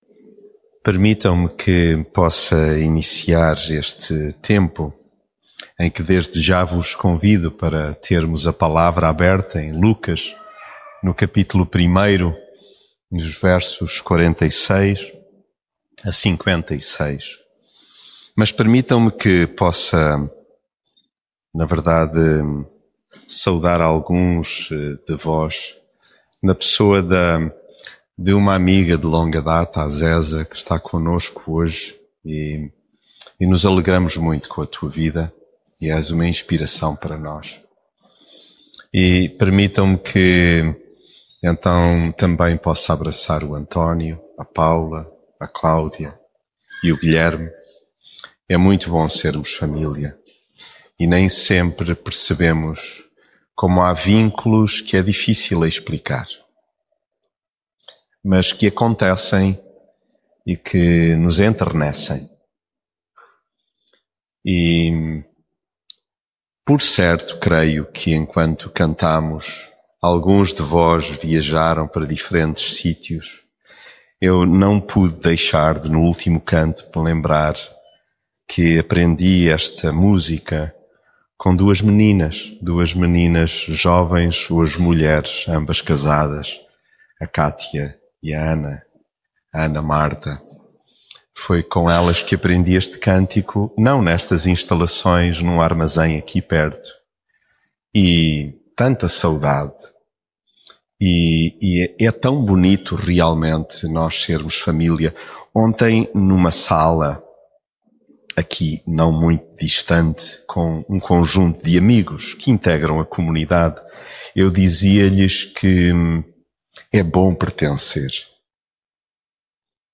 mensagem bíblica Alturas há na vida em que dentro de nós se dá uma misturada infinda de sentimentos…